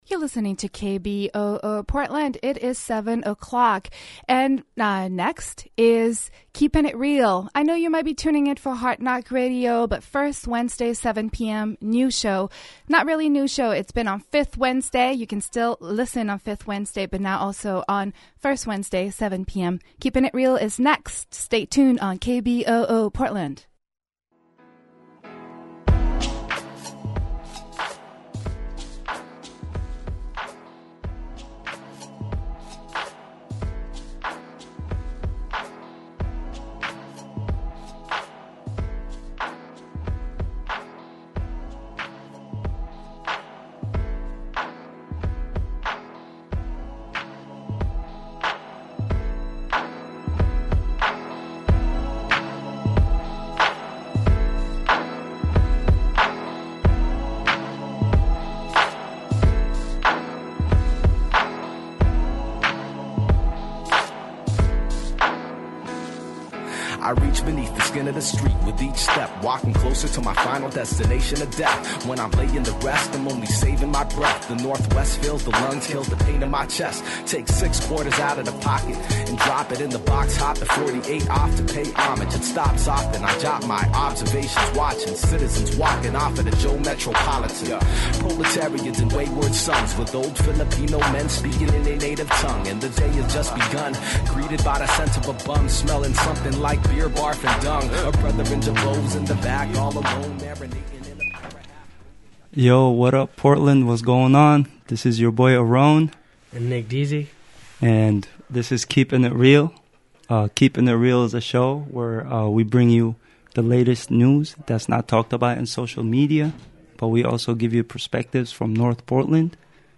Download audio file “Keepin It Real” is a public affairs, talk radio program that strives to educate and to offer a forum for people to speak their truth while being open minded and inclusive.